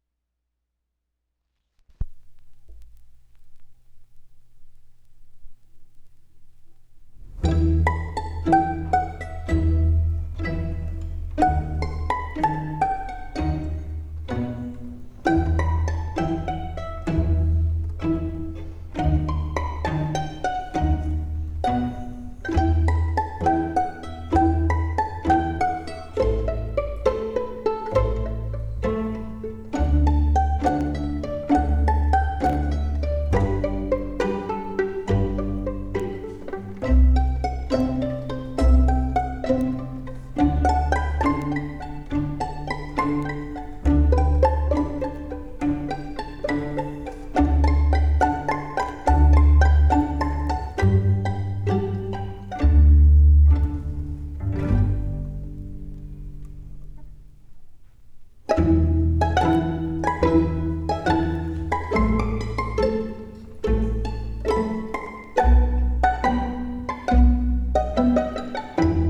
The original 96/24 Nagra digital recording used three Neumann M50 tube microphones in the classic "Decca tree" configuration.
The plucked, percussive mandolin is an ideal instrument to demonstrate differences (if there are any) among the mats in terms of transient speed and clarity, sustain and decay—especially given the recording technique, which produces stable, three-dimensional images.
Mandolin4~Chasing_the_Dragon~side1.wav